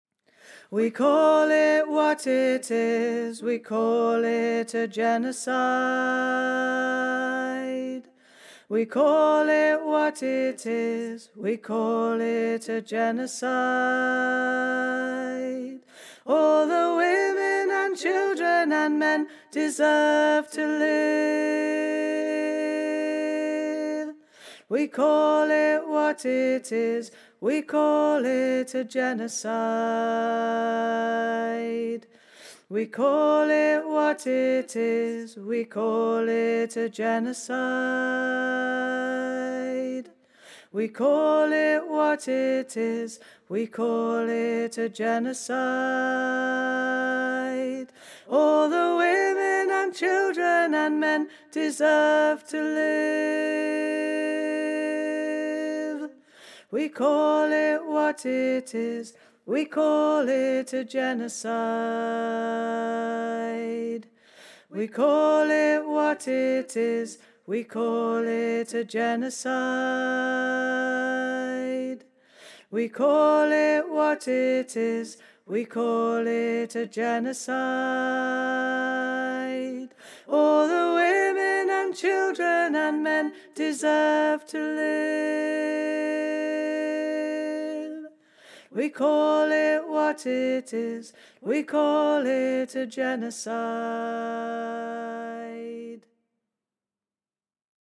A street song
Mid: